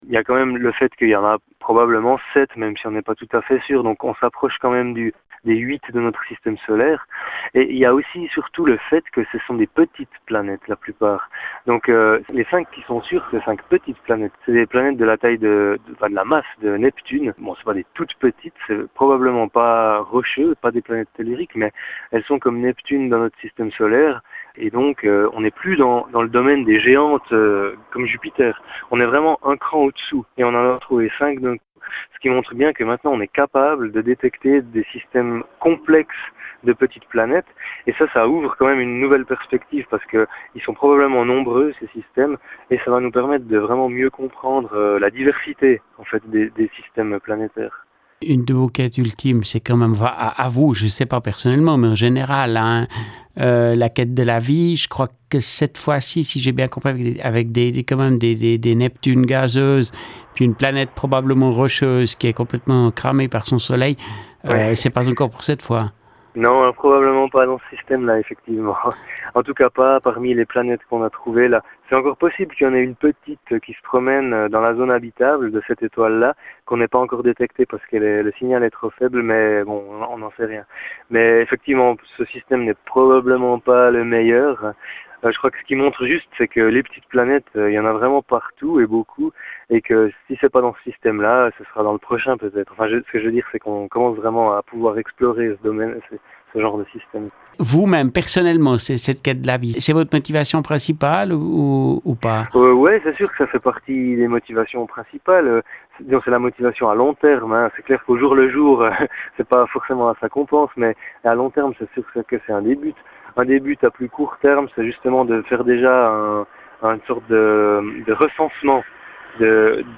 astrophysicien